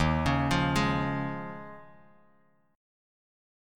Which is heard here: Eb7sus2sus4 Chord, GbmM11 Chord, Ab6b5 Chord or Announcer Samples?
Eb7sus2sus4 Chord